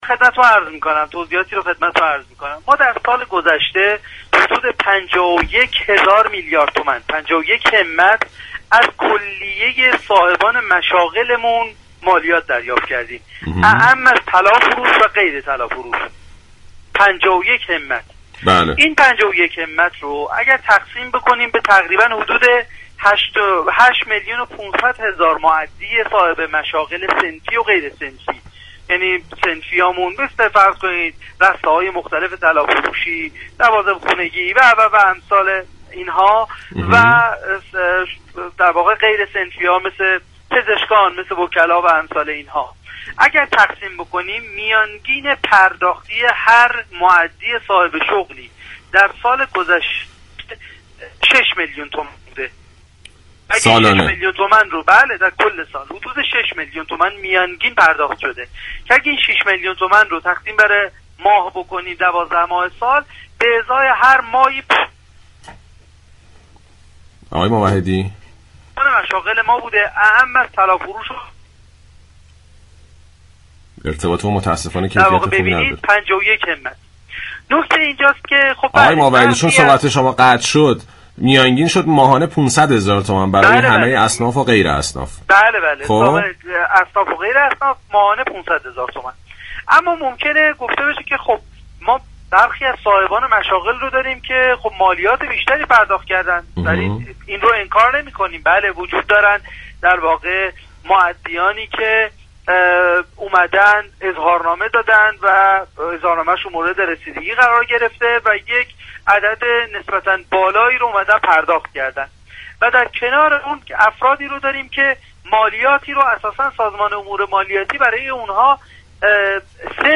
در گفتگو با برنامه گفتاورد